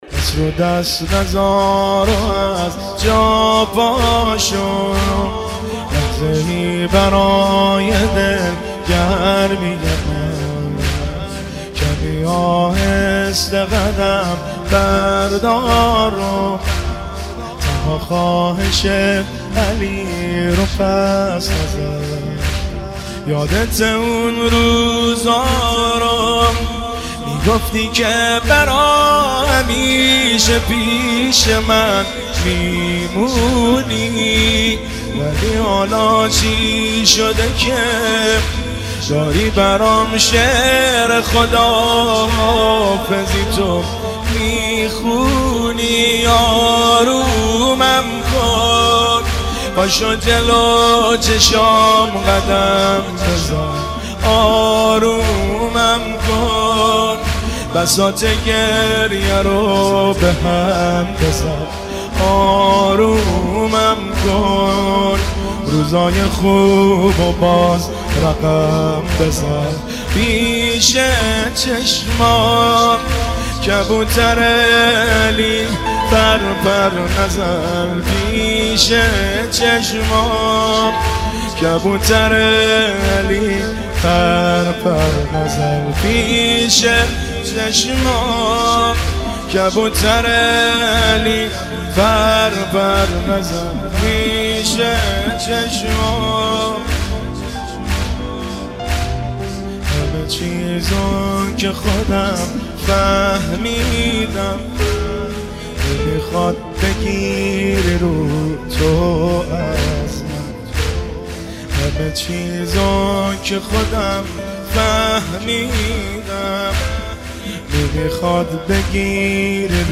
زمینه شب دوم فاطمیه دوم 1395